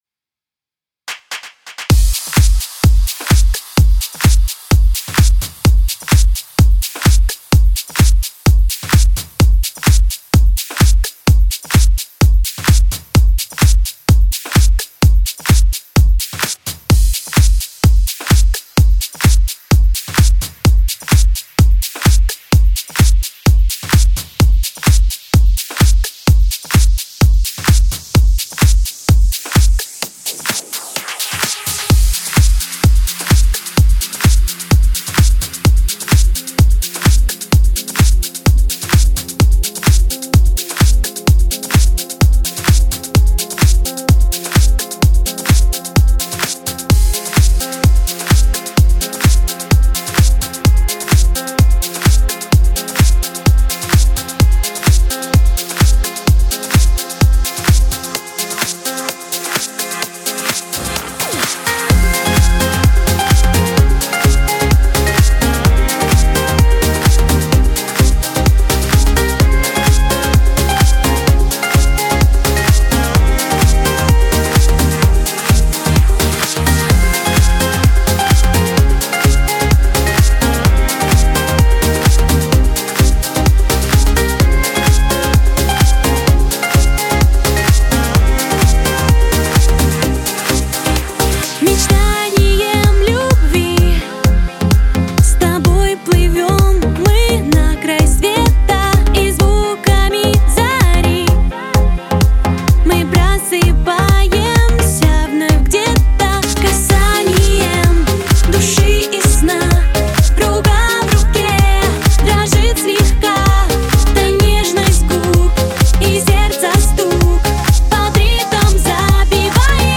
Жанр: Dance music
лучшая клубная поп музыка